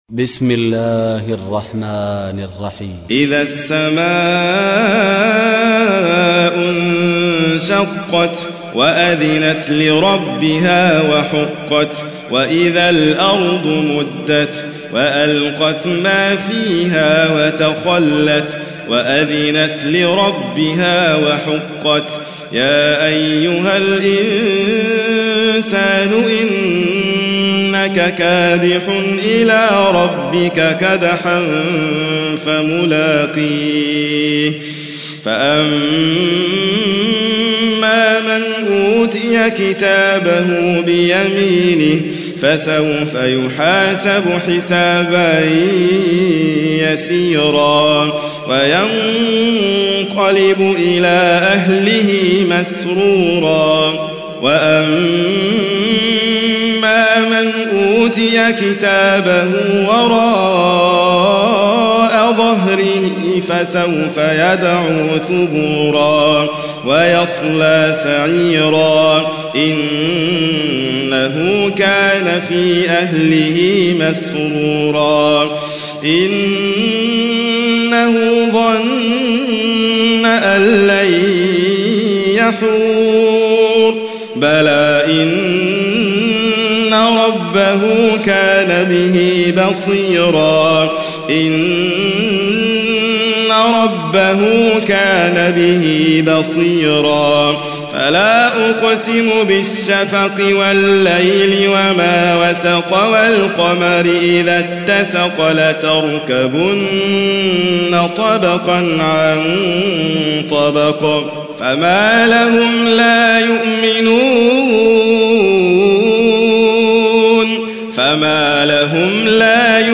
تحميل : 84. سورة الانشقاق / القارئ حاتم فريد الواعر / القرآن الكريم / موقع يا حسين